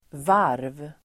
Uttal: [var:v]